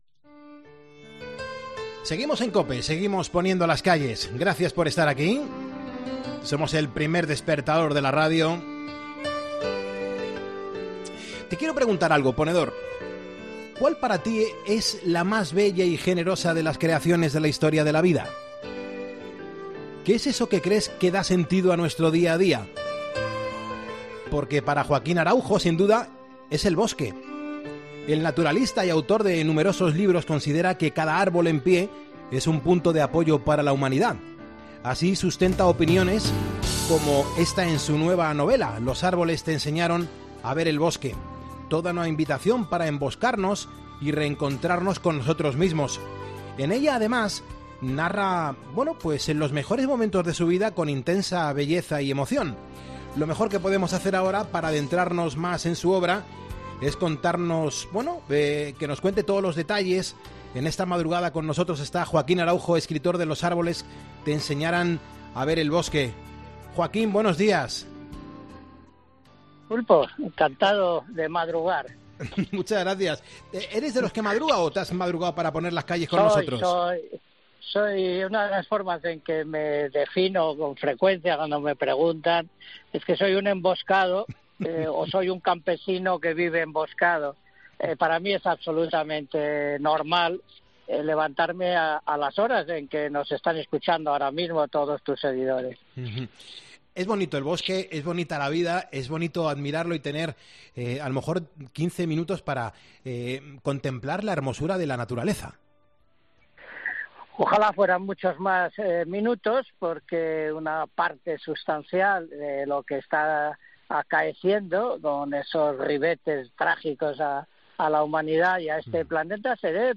Entrevista Joaquín Araujo